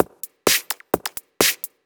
Electrohouse Loop 128 BPM (35).wav